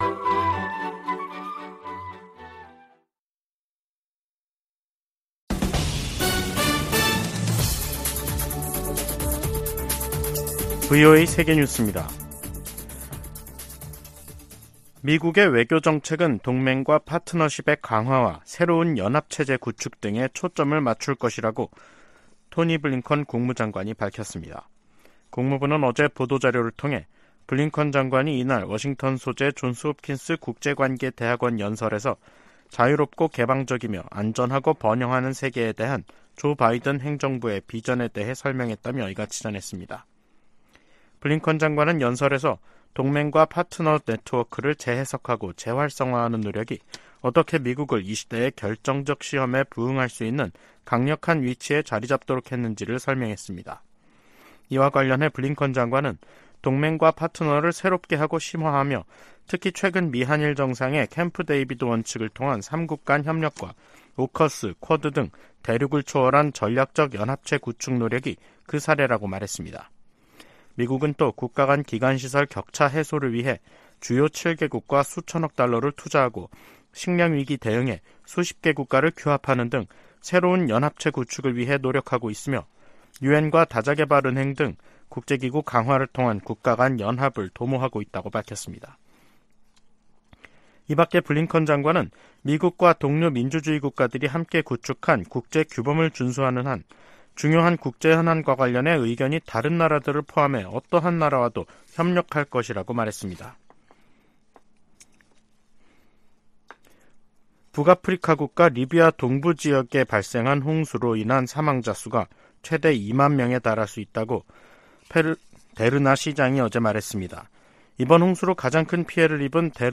VOA 한국어 간판 뉴스 프로그램 '뉴스 투데이', 2023년 9월 14일 2부 방송입니다. 러시아를 방문 중인 김정은 국무위원장이 푸틴 대통령의 방북을 초청한 것으로 북한 관영 매체가 보도했습니다.